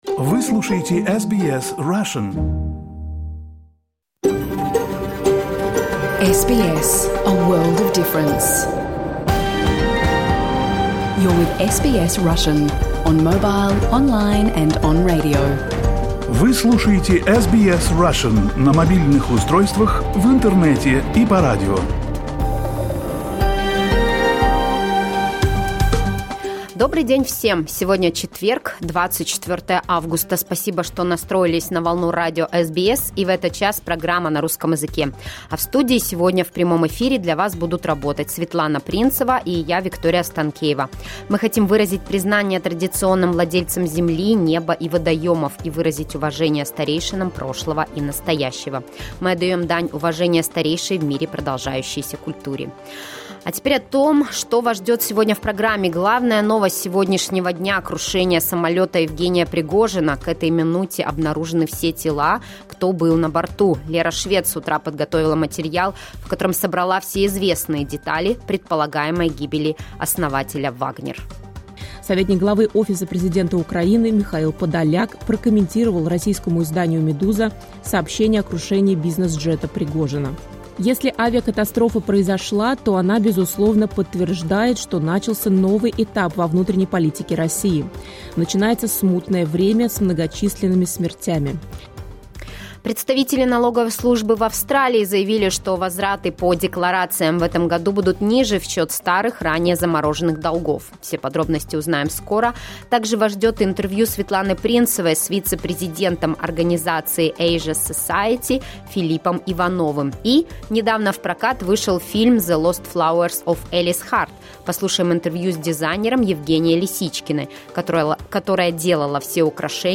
You can listen to SBS Russian program live on the radio, on our website and on the SBS Audio app.